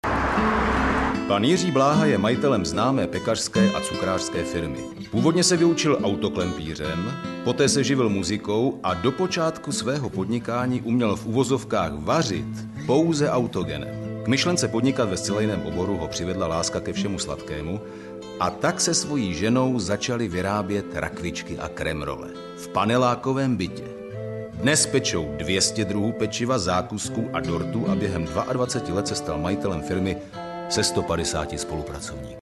V ukázce to hraje jako podklad rozhovoru.